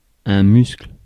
Ääntäminen
Ääntäminen US : IPA : [mʌsl]